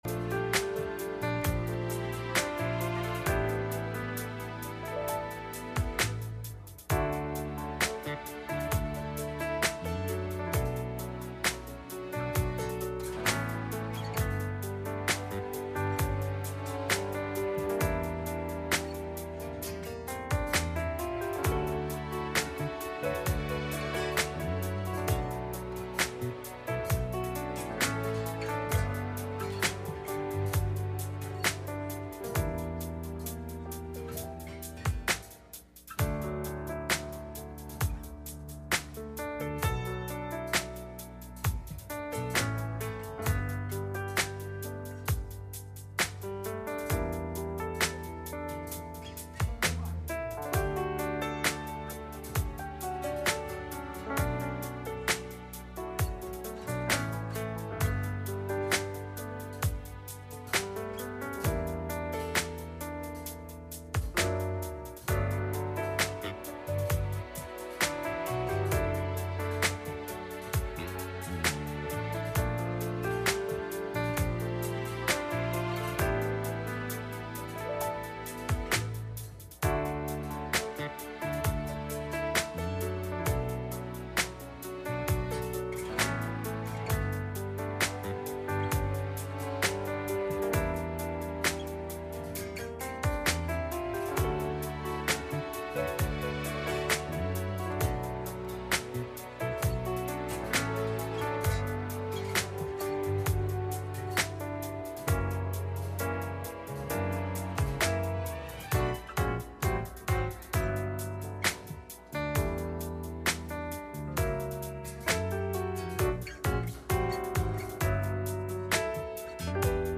Message Service Type: Sunday Morning https